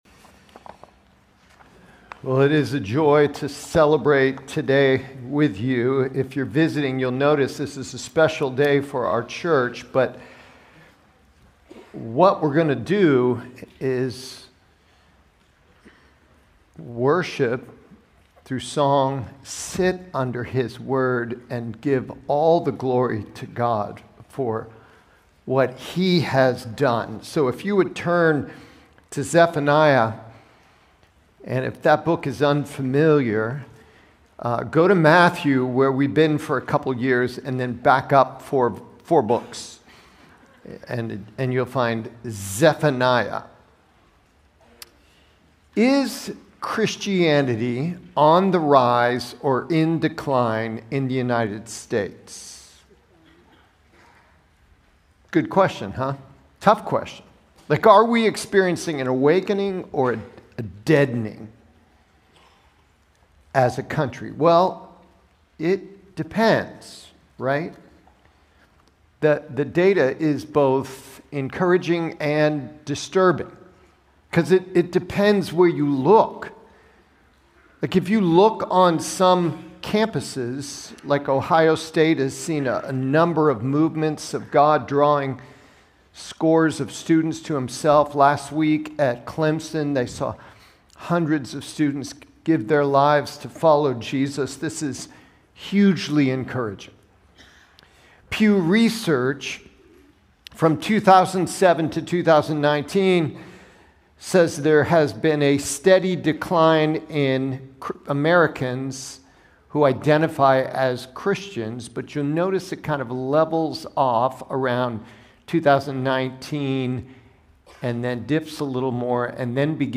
Prev Previous Sermon Next Sermon Next Title A Day of Judgment or Joy